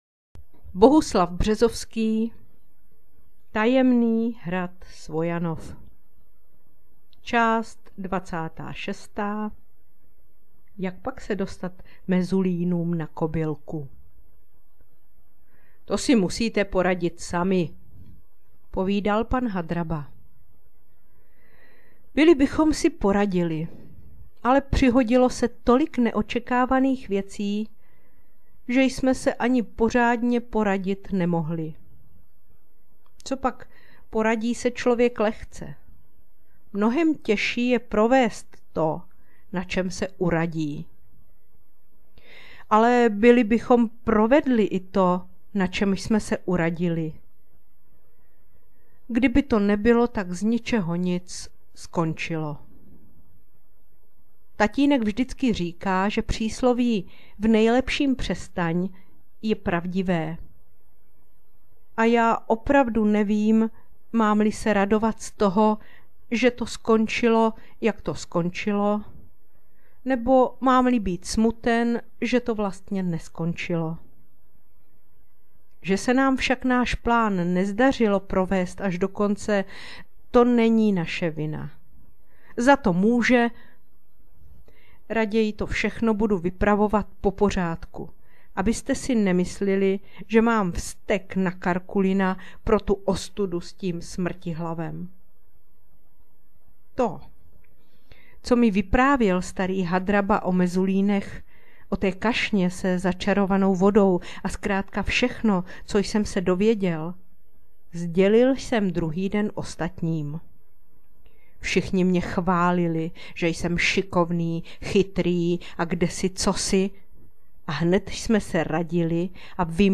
Na příjemně teplé, ale i deštivé jarní dny i večery připravila knihovnice četbu z knihy Bohuslava Březovského Tajemný hrad Svojanov aneb Paměti Františka Povídálka jako takzvanou „knížku do ucha“.